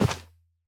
Minecraft Version Minecraft Version snapshot Latest Release | Latest Snapshot snapshot / assets / minecraft / sounds / mob / camel / step_sand1.ogg Compare With Compare With Latest Release | Latest Snapshot
step_sand1.ogg